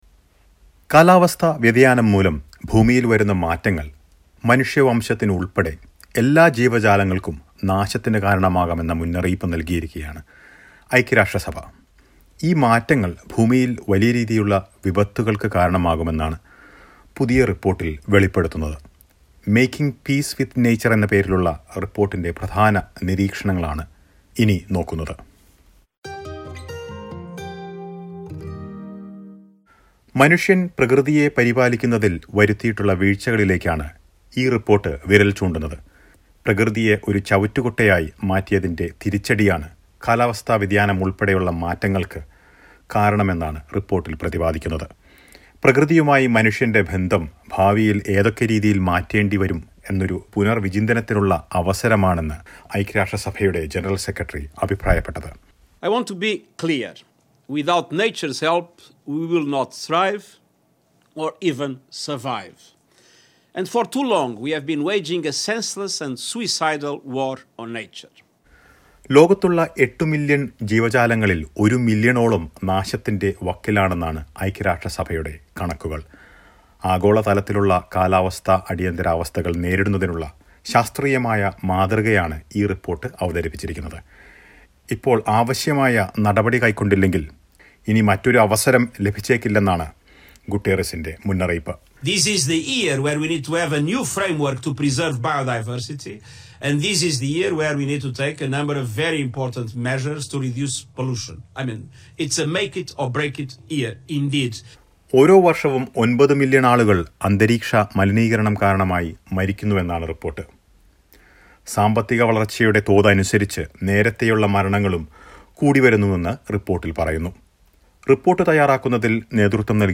After a report revealed that immediate action is needed to protect the environment, UN Secretary-General is calling for a rethink of humanity's relationship to nature. Listen to a report.